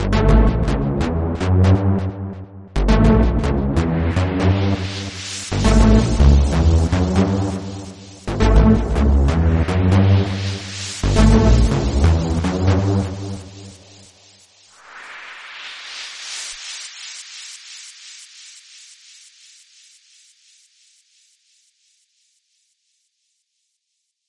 P O L I C E
the-drummy-wind_17164.mp3